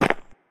step-3.ogg.mp3